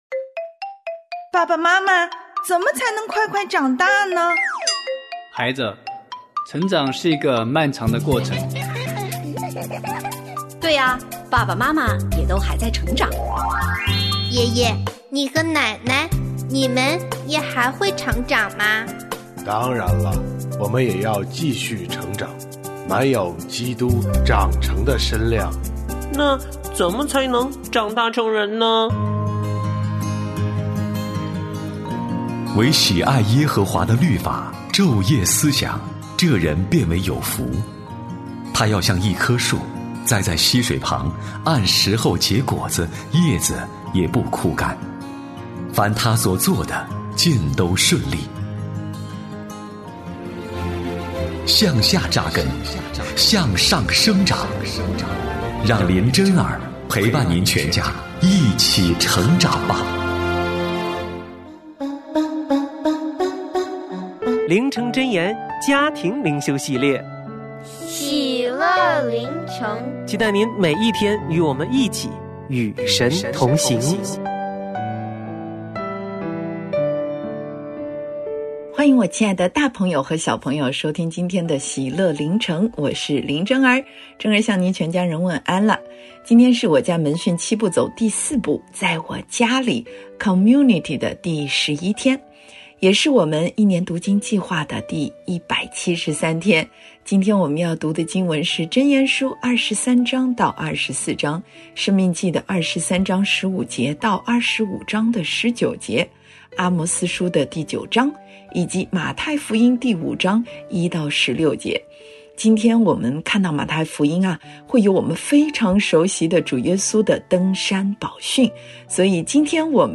婚姻私塾：理所当然的事奉，浩瀚无垠的恩典──神秘嘉宾访谈录（6）